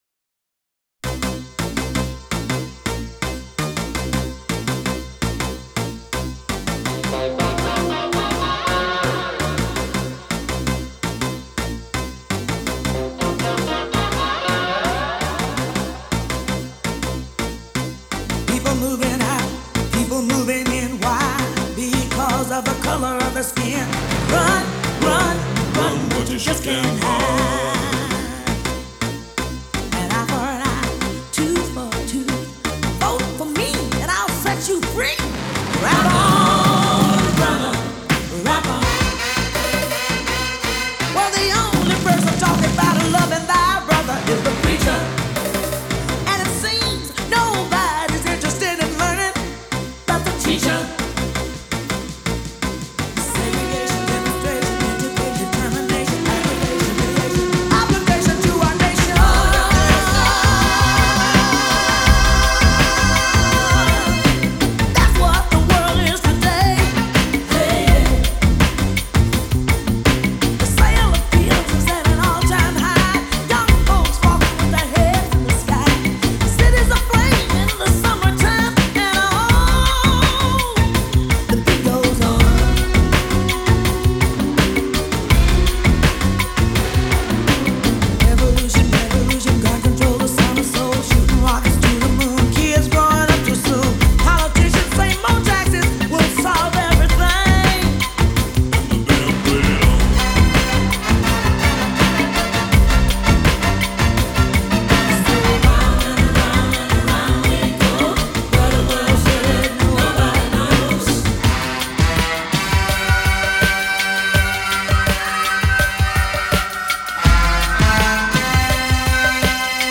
synth-driven cover songs
featuring guest vocals by a host of singers.